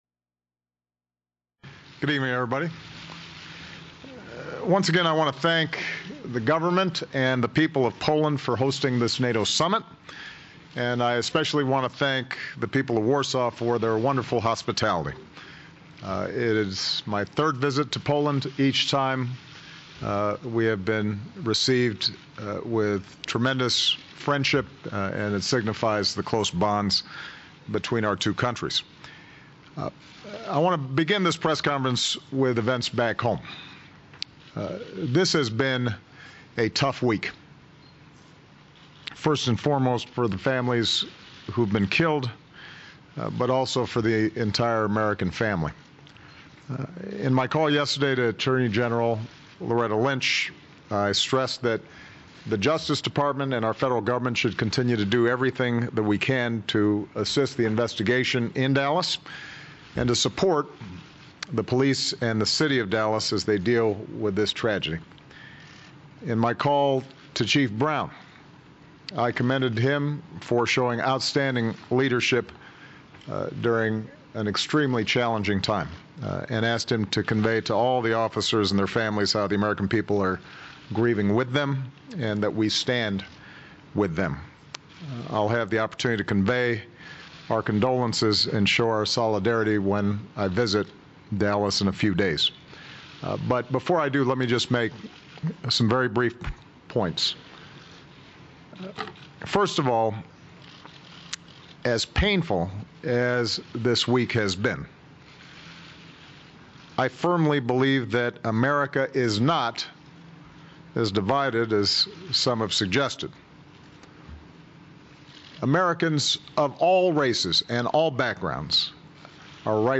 U.S. President Barack Obama speaks with reporters following two days of meetings with NATO allies in Warsaw, Poland
Obama also speaks about gun safety laws, immigration, the United Kingdom's decision to leave the European Union, his legacy, and being at war during his entire presidency. The president makes his remarks at a press conference in Warsaw, Poland.